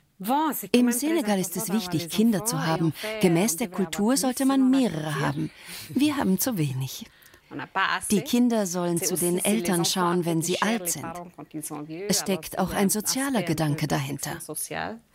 voice over-srf-schweizer liebesgeschichten-nr.1